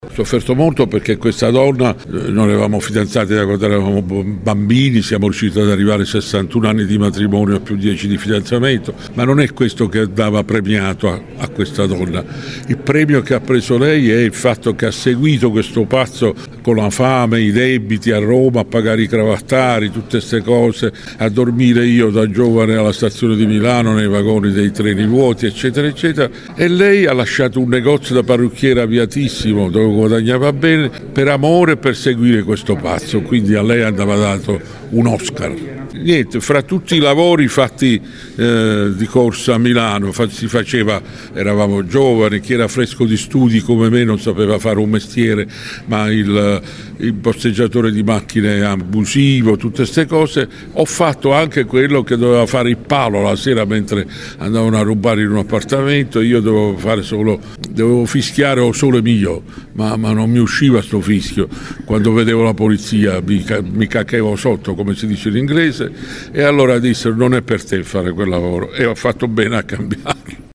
Un incontro carico di emozione e simpatia quello che si è tenuto oggi al foyer del Teatro D’Annunzio di Latina, dove Lino Banfi ha presentato in anteprima lo spettacolo che andrà in scena il 27 novembre, inserito nel cartellone della stagione teatrale 2025-2026 del teatro comunale.